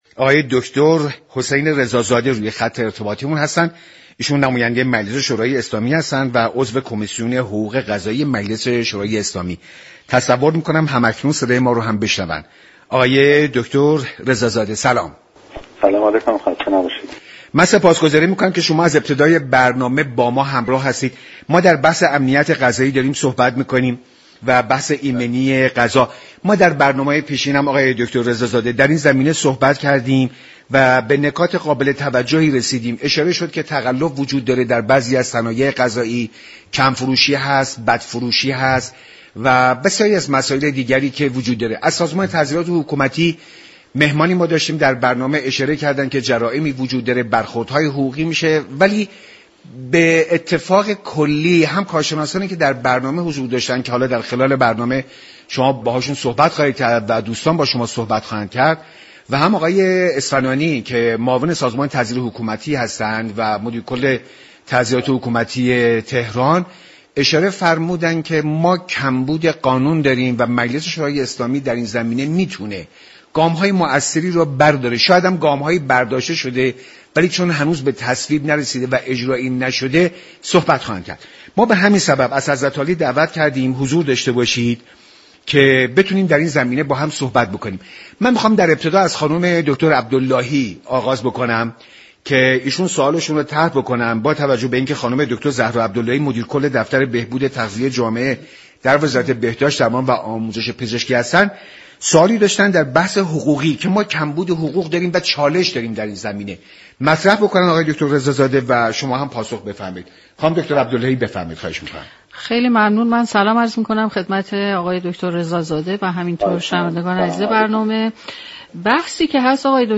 به گزارش شبكه رادیو ایران، «حسین رضازاده» عضو كمیسیون حقوقی و قضایی مجلس شورای اسلامی در گفت و گو با برنامه «میزبان» درباره محصولات غذایی تقلبی در بازار و راهكار مقابله با آن گفت: كشور به لحاظ قانونی هیچ مشكلی برای مقابله با مواد غذایی تقلبی ندارد؛ ما باید تفسیر درستی از قانون داشته باشیم.